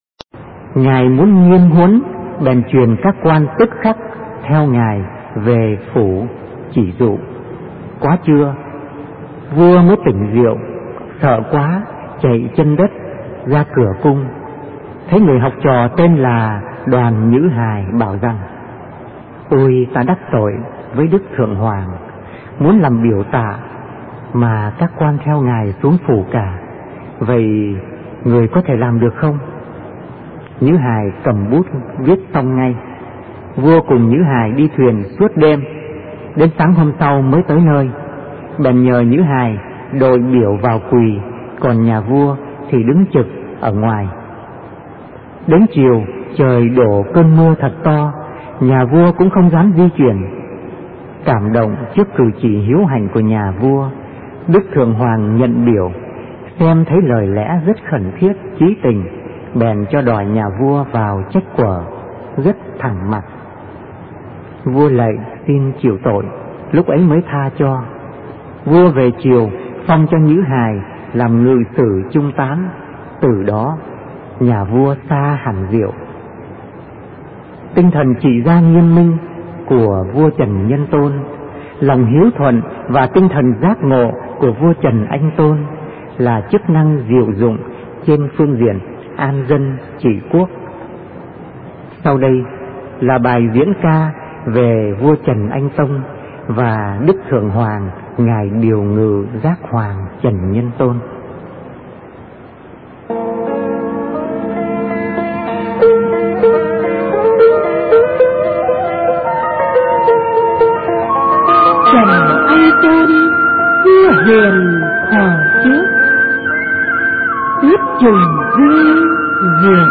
Truyện Đọc Tình Mẹ